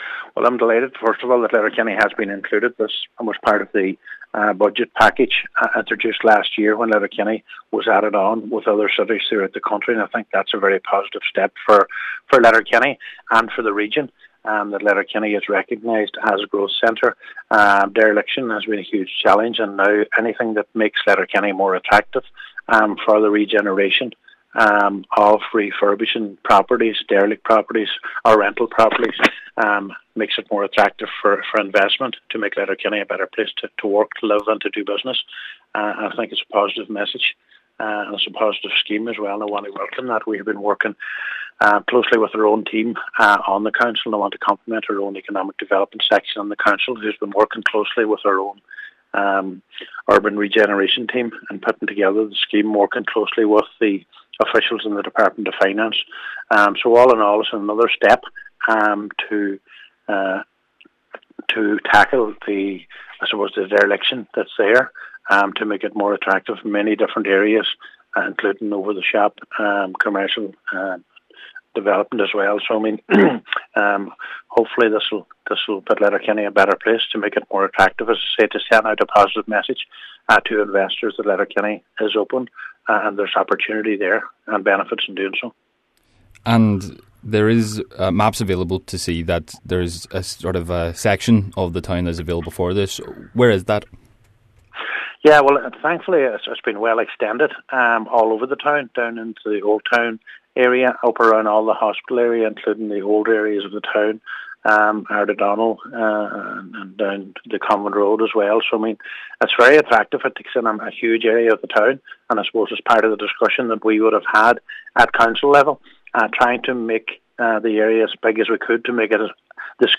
Mayor of Letterkenny, Cllr Ciaran Brogan says this will show investors that the town is open for business